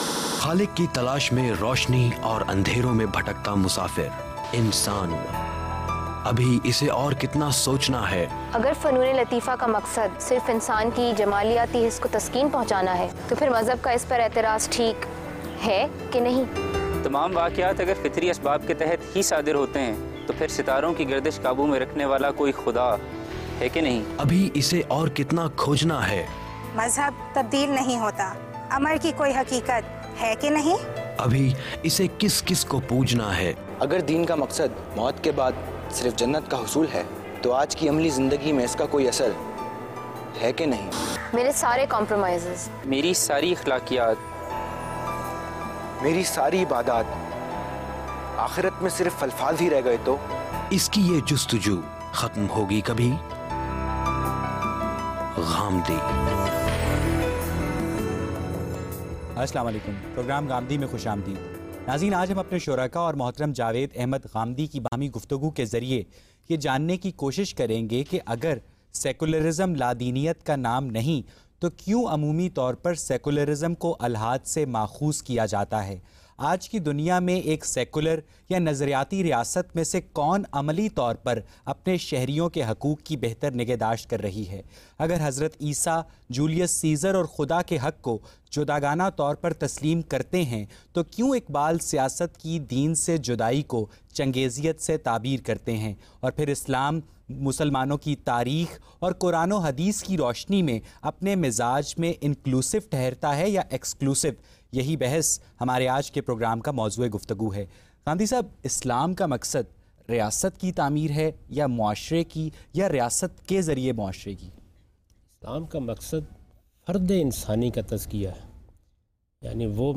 Questions and Answers on the topic “Islam and Secularism” by today’s youth and satisfying answers by Javed Ahmad Ghamidi.